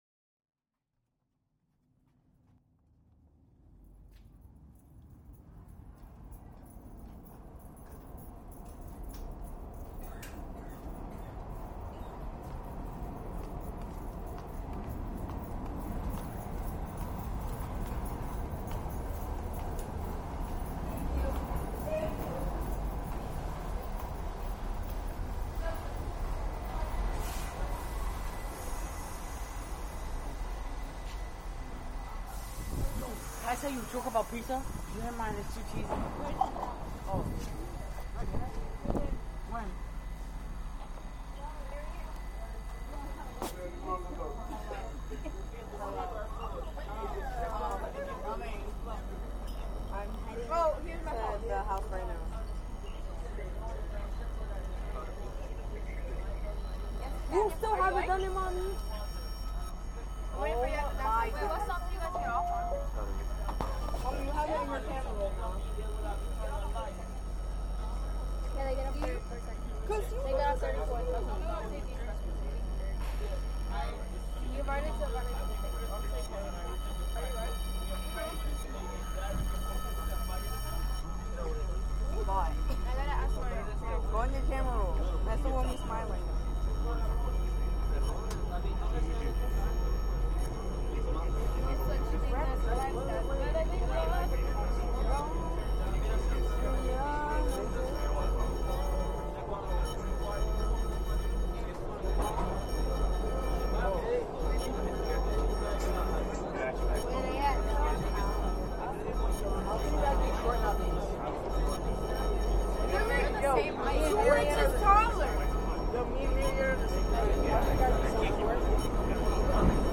new recording of schoolkids on the yellow q subway line from lexington to times sq schools out. this is layered up with some electrical stuff I enjoy. it fades after a while into a guy busking on the flatform hes singing and im walking by looking for my line. then edmund de waals voice is messed up in los angeles a few years ago now but I just found it a few days ago, then some wildlife cranes I believe then silence. then the freezer compartment of my accommodation in rotterdam this year you cant go wrong with a constantly freezing compartment just shove in the recorder and wait